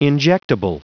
Prononciation du mot injectable en anglais (fichier audio)
Prononciation du mot : injectable